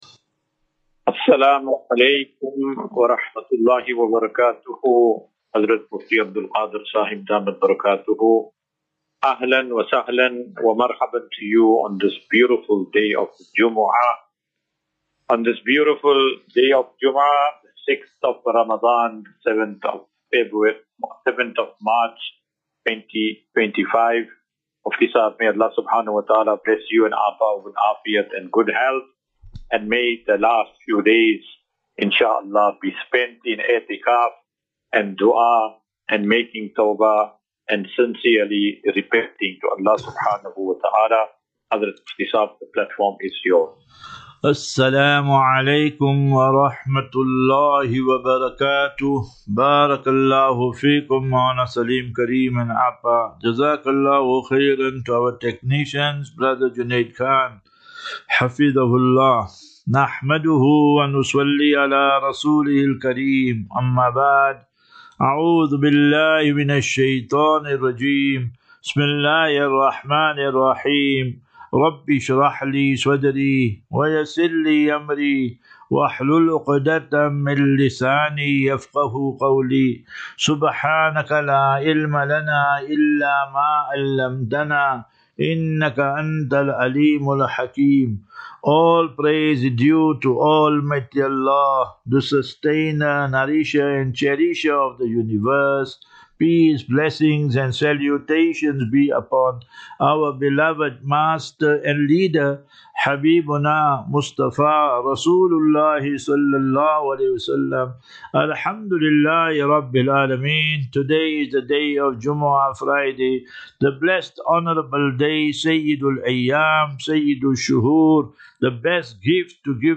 As Safinatu Ilal Jannah Naseeha and Q and A 7 Mar 07 March 2025.